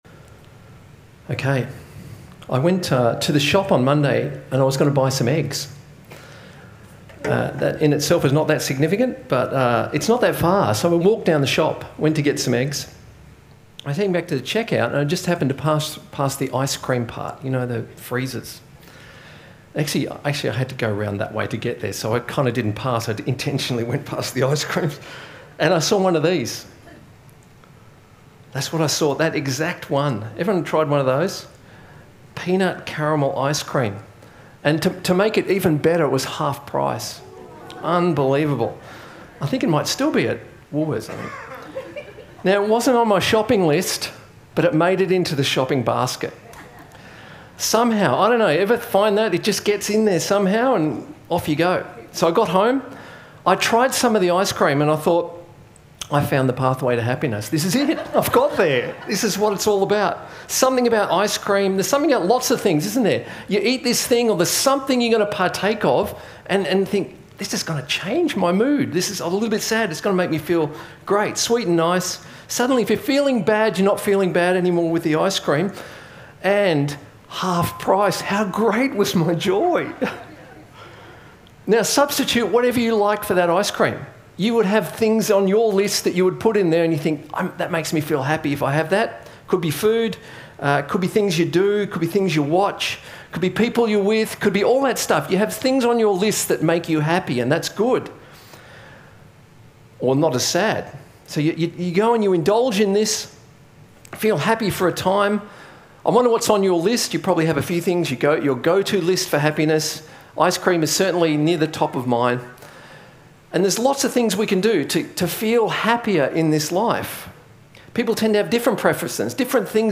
A message from the series "Emotions."